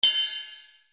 RIDE CUP.wav